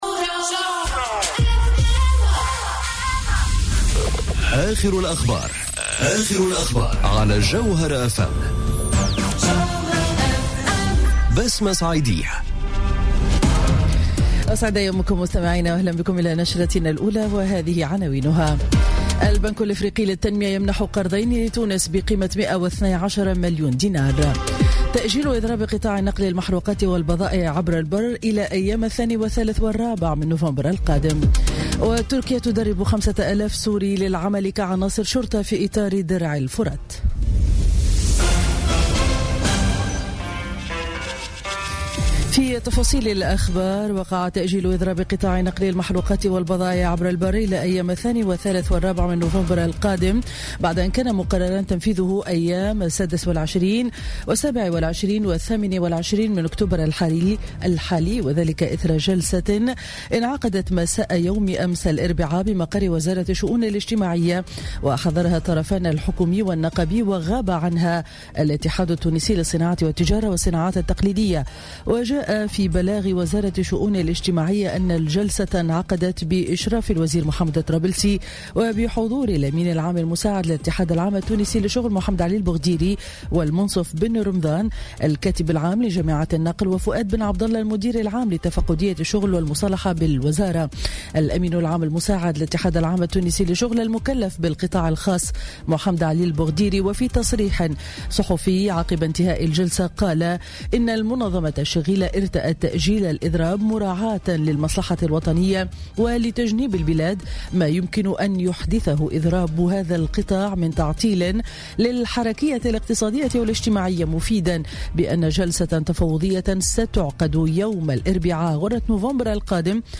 نشرة أخبارالسابعة صباحا ليوم الخميس 26 أكتوبر 2017